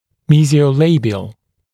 [ˌmiːzɪəu’leɪbɪəl][ˌми:зиоу’лэйбиэл]медиально-губной, медиально-передний